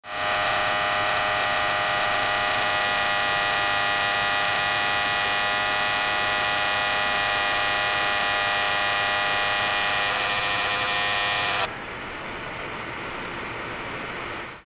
Grind.
My trusty PBG4’s fan has started, er, um, groaning.
It makes the noise for a second or two and then stops for a while (seconds to minutes to hours).
It’s loud. Of course, the PowerBook’s microphone is right next to the fan, so even the normal, quiescent purring (audible at the end of the recording) sounds kind of awful.
grinding-fan.mp3